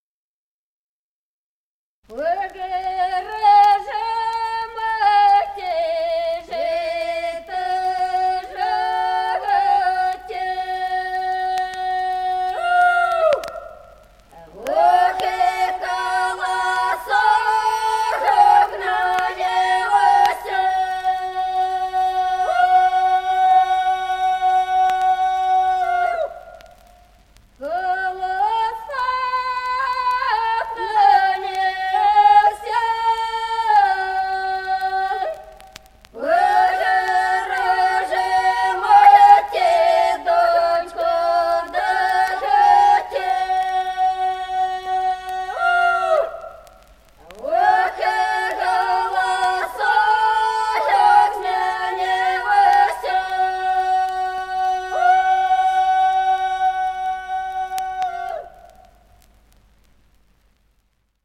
Песни села Остроглядово. Пора же, мати, жито жати.